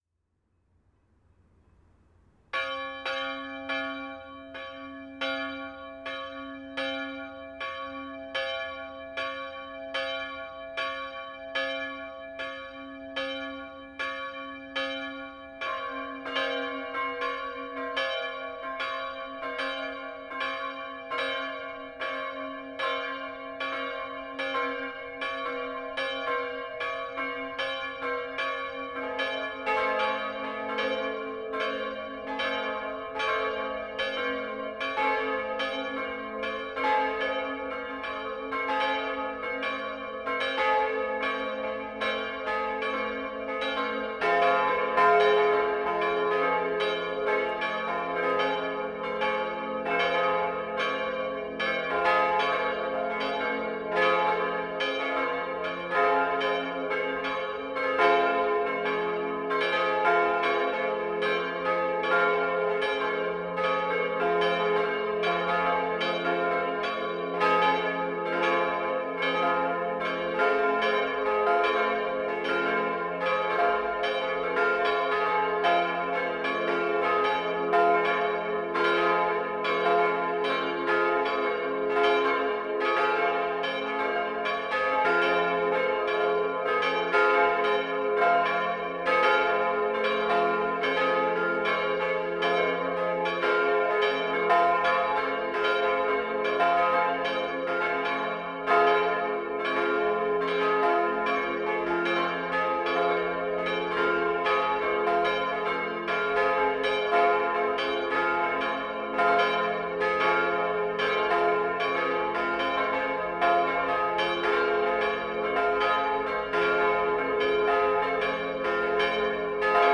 Unsere Kirchenglocken:
»Glockengeläut der Kirche St. Jakob
Glockengelute-Pfarrkirche-St-Jakob.mp3